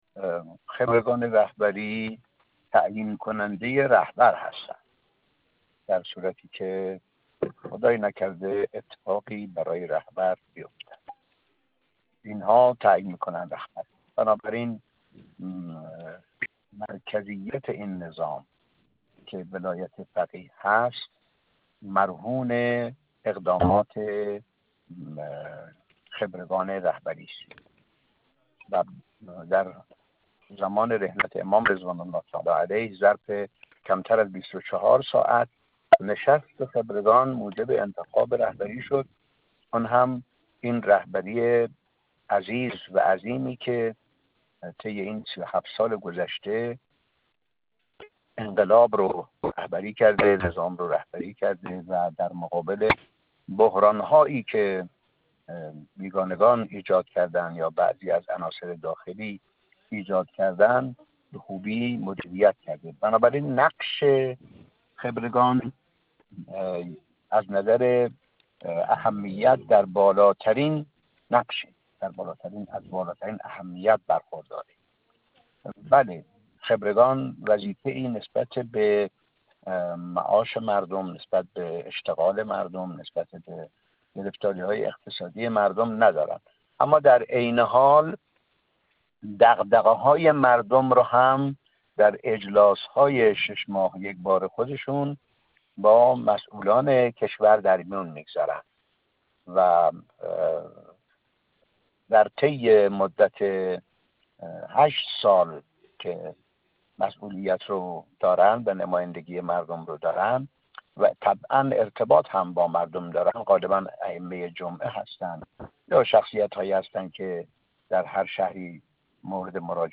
حجت‌الاسلام والمسلمین غلامرضا مصباحی مقدم، عضو مجمع تشخیص مصلحت نظام، در گفت‌وگو با ایکنا، درباره ضرورت  مشارکت حداکثری در انتخابات مجلس خبرگان رهبری و تبیین اهمیت جایگاه این مجلس این برای مردم، گفت: خبرگان رهبری تعیین‌کننده رهبر است.